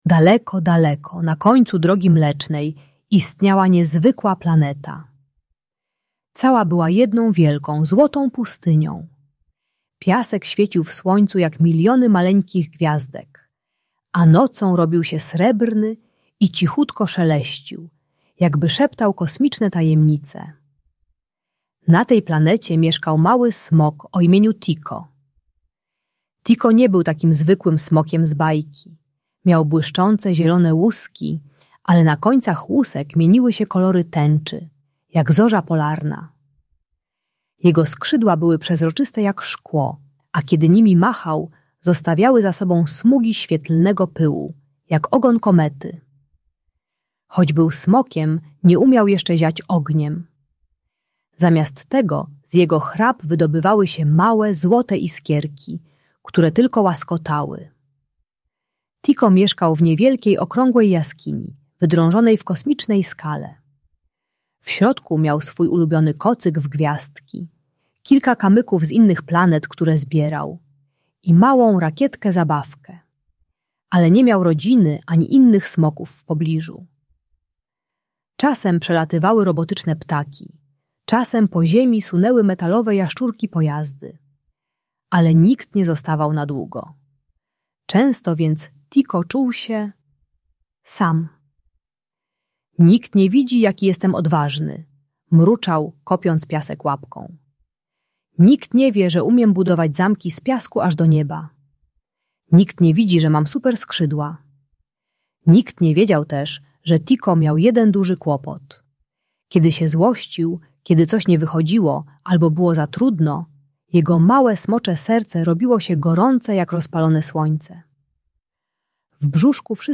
Ta audiobajka o autoagresji uczy trzy techniki radzenia sobie z wybuchami złości: przytulanie siebie, głębokie oddychanie oraz bezpieczne rozładowanie emocji przez uderzanie w poduszkę. Pomaga dziecku zrozumieć, że jego rączki nie są złe.